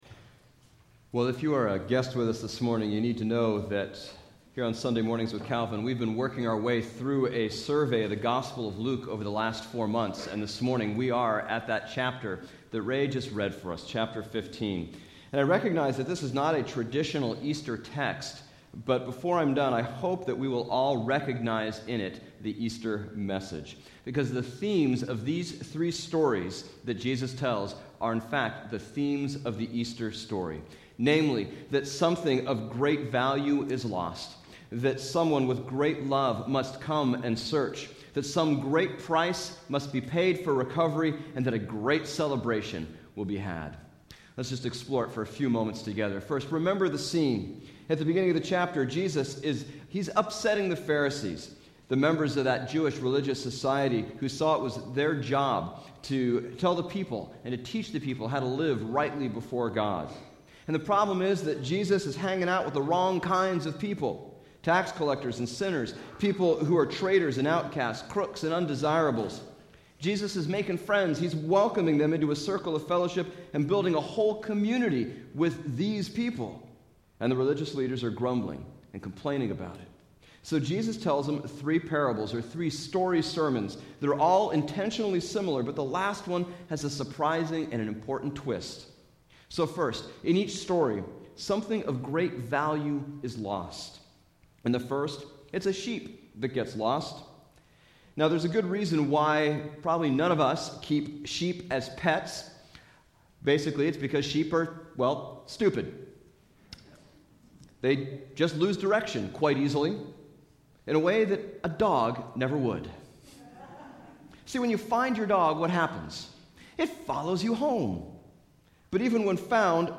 Bible Text: Luke 15:1-32 | Preacher